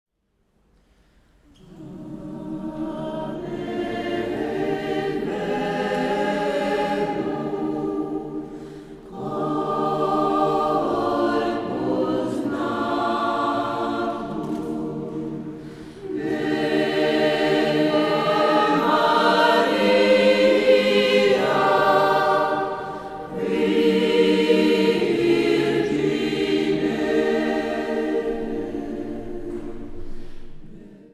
Kören är mp3 från DSD och gitarren inspelad i PCM 16/88.2 så för att kunna spela den senare måste du uppenbarligen antingen ha ett ljudkort med 88.2kS/s eller så får du importera filerna in i lämpligt program och konvertera ner till lägre sampelhasighet.
Signalen är tagen från hörlursutgången som sedan matas in i AD-omvandlarens linjeingång.
Mera efterklang och fylligare, litet åt det analoga hållet.